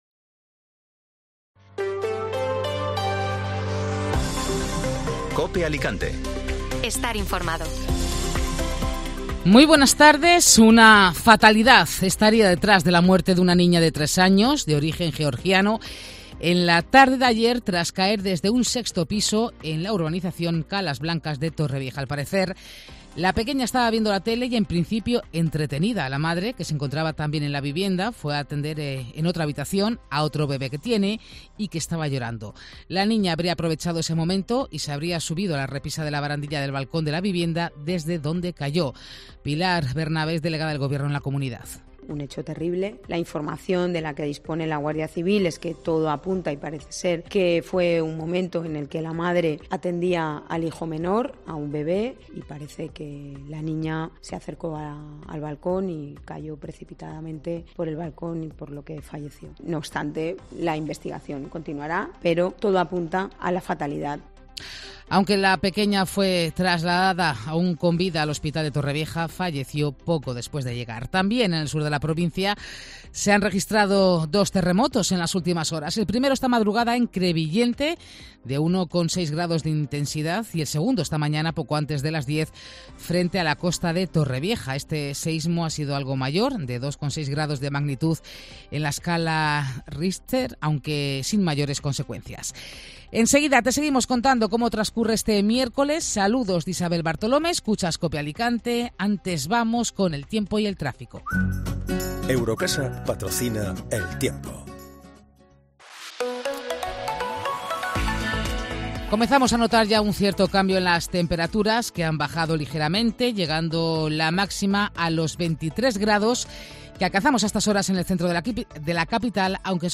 Alicante - Novelda Informativo Mediodía Cope Alicante (Miércoles 10 de mayo) Muere una niña de tres años en Torrevieja al caer de un sexto piso.